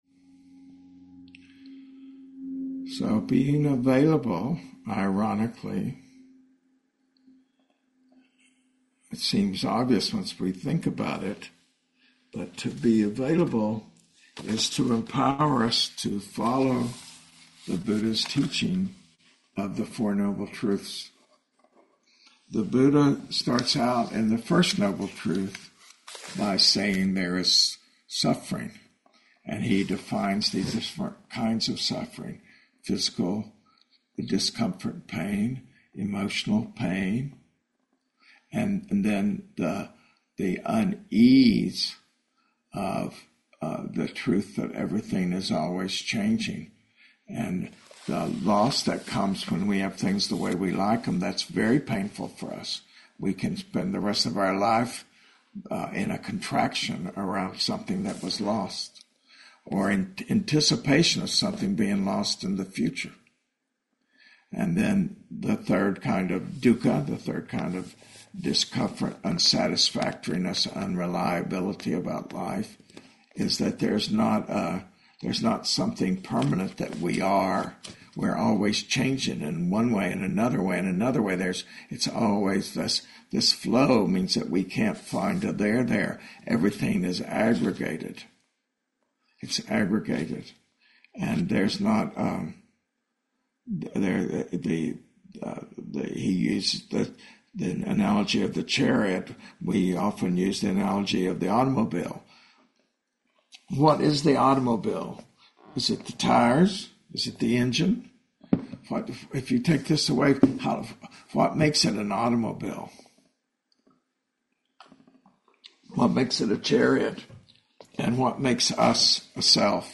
Guided Meditation: Arriving and Availability